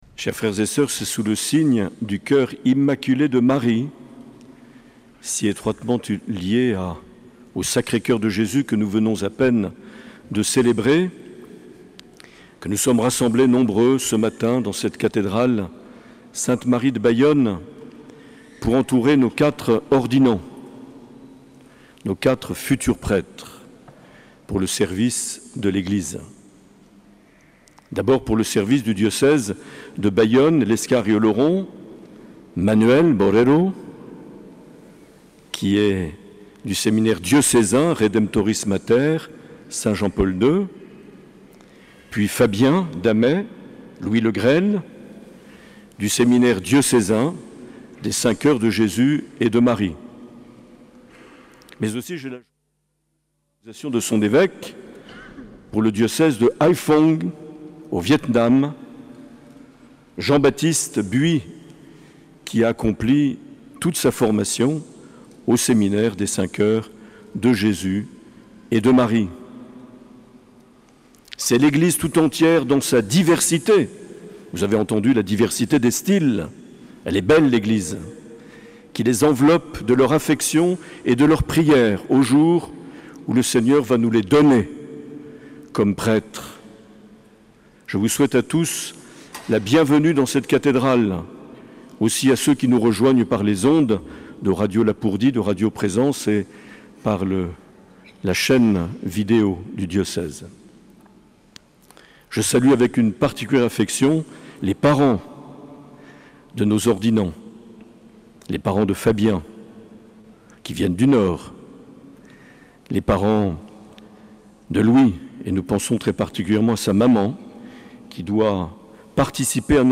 Cathédrale de Bayonne
Accueil \ Emissions \ Vie de l’Eglise \ Evêque \ Les Homélies \ 25 juin 2022
Une émission présentée par Monseigneur Marc Aillet